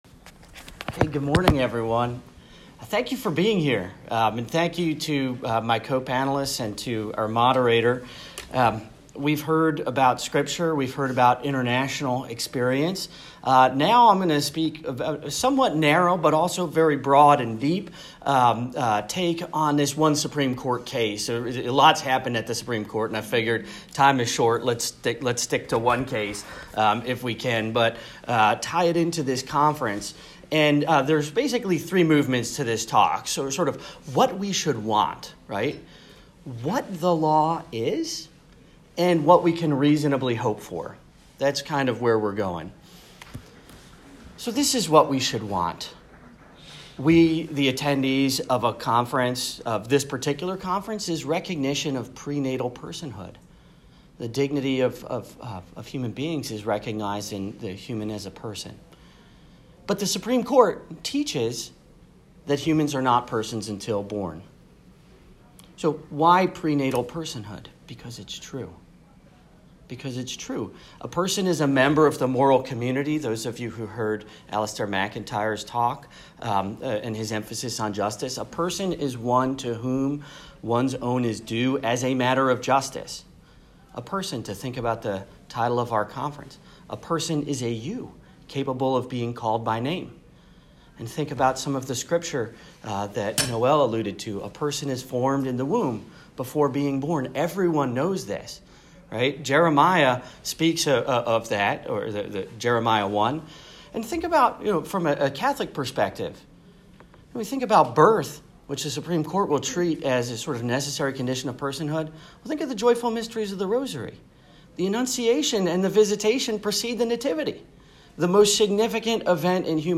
I was privileged over the last few days to participate in the 21st annual Fall Conference of the deNicola Center for Ethics and Culture at Notre Dame.
An audio recording (of my talk only, not of others on my panel or of the Q&A) is available here .